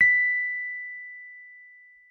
Rhodes_MK1
b5.mp3